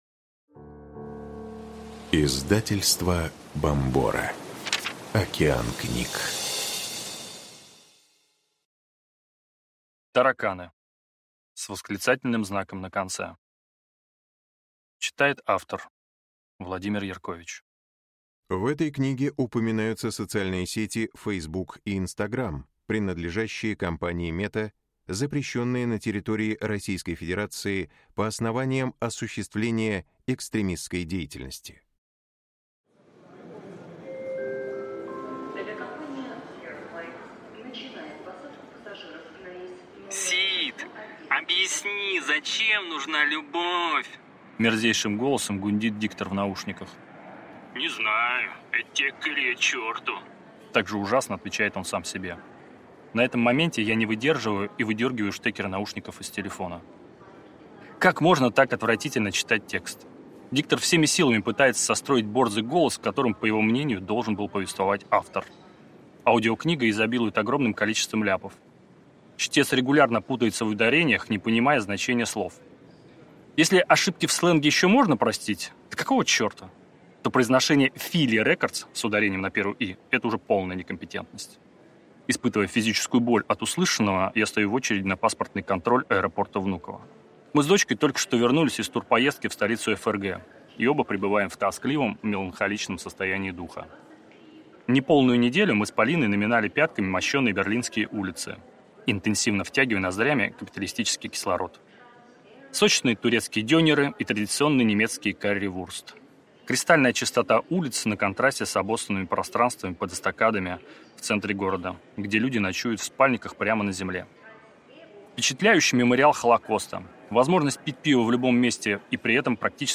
Аудиокнига Тараканы! С восклицательным знаком на конце. 30 лет в панк-роке вопреки всему | Библиотека аудиокниг